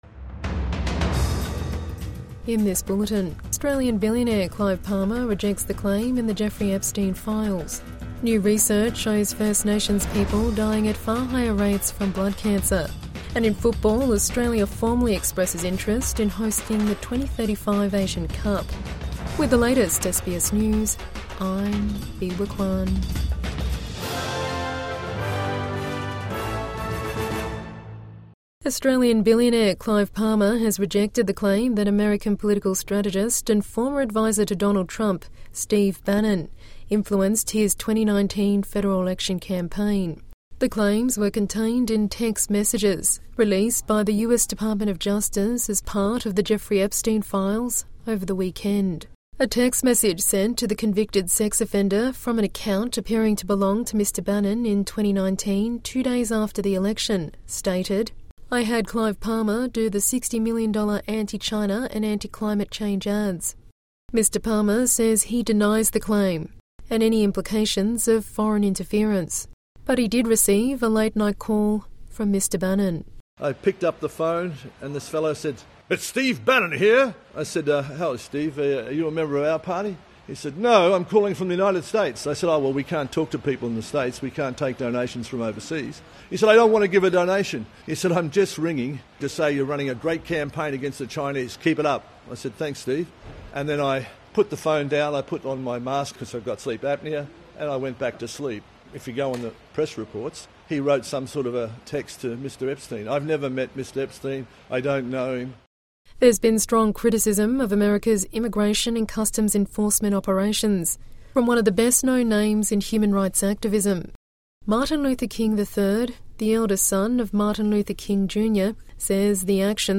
Clive Palmer rejects claim revealed in Epstein files | Evening News Bulletin 4 February 2026